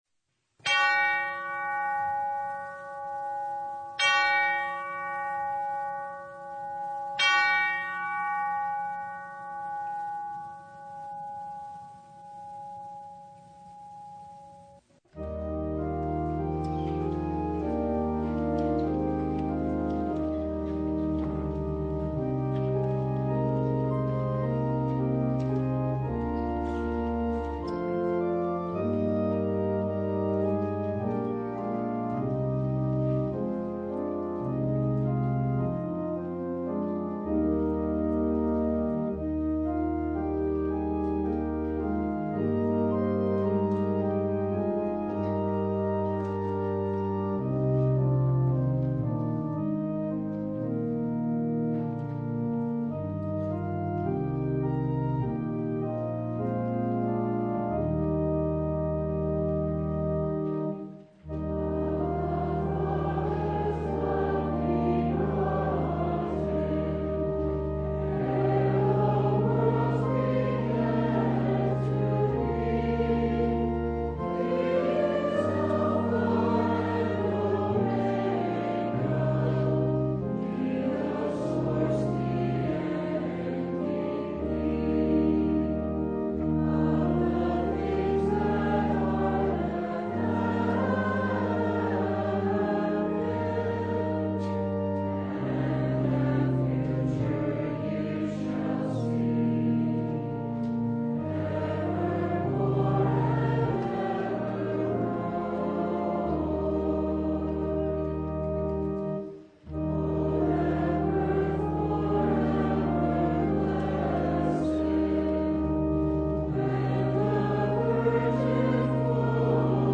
December 28, 2025 The Feast of the Holy Innocents (2025) Preacher: Visiting Pastor Passage: Matthew 2:13-18 Service Type: The Feast of the Holy Innocents The death of the Holy Innocents proclaims Jesus.